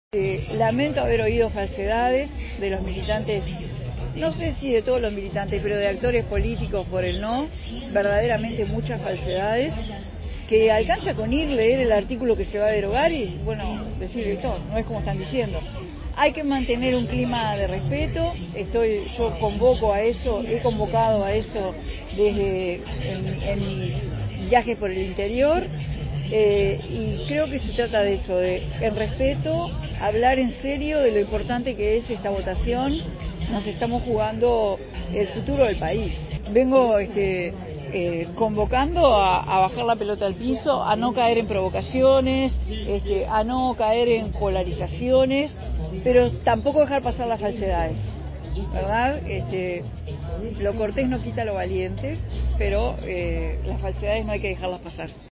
La intendenta de Montevideo, Carolina Cosse, se hizo presente en un acto a favor del «SÍ» en el Velódromo Municipal.